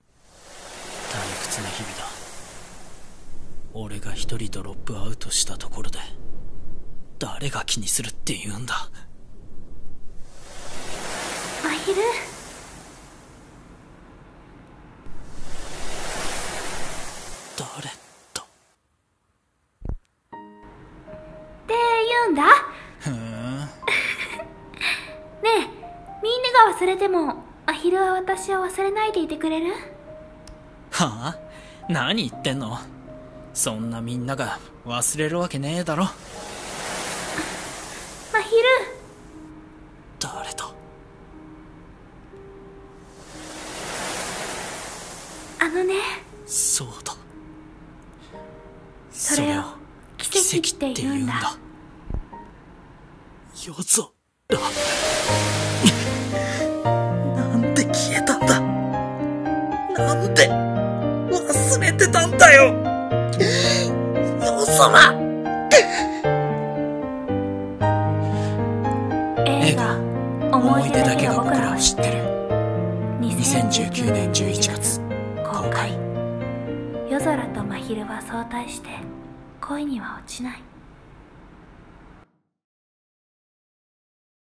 【映画予告風】思い出だけが僕らを知ってる【声劇台本】